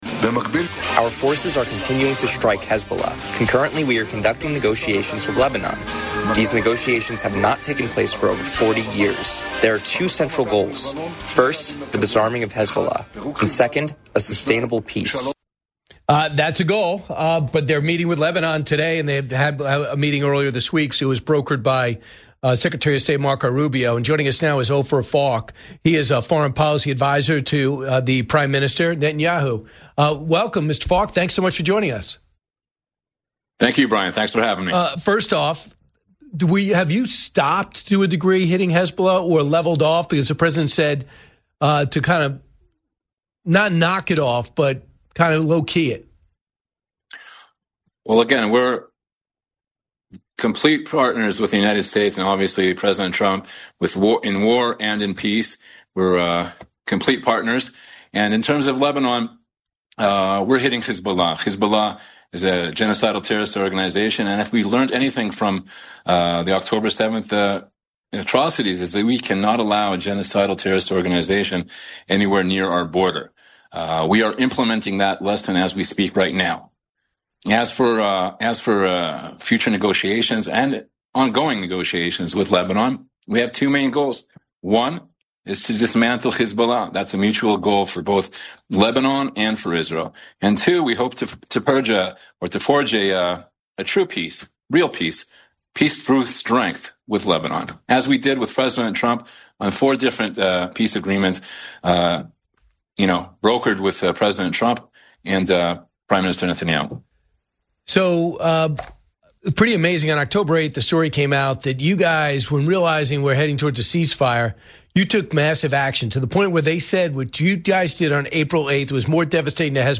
Brian Kilmeade is joined by Dr. Ophir Falk, Foreign Policy Advisor to Prime Minister Netanyahu, to discuss the strategic degradation of Hezbollah and the shifting power dynamics in Iran. Falk breaks down the "Roaring Lion" operation and why a nuclear-armed Iran remains the ultimate threat to global stability.